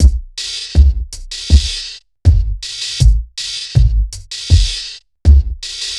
EXTREMELY ABRASIVE BEATS or PROMO 01.wav